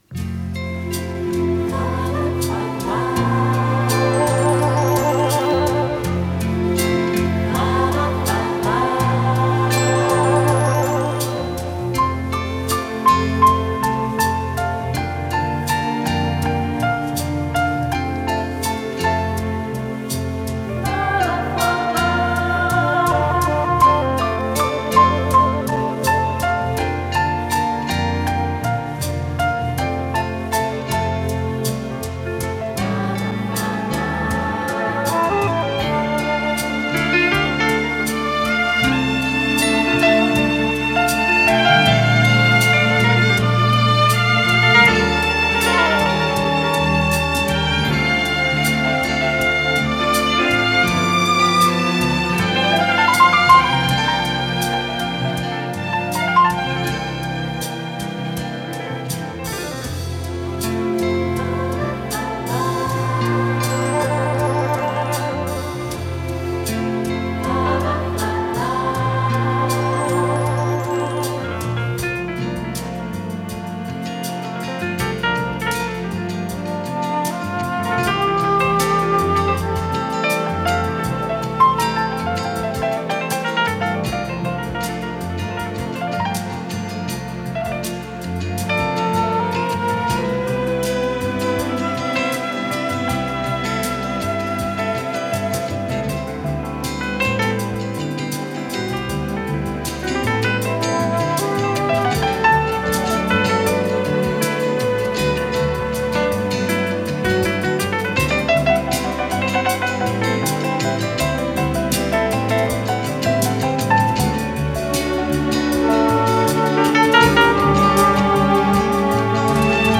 с профессиональной магнитной ленты
ВариантДубль моно